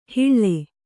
♪ hiḷḷe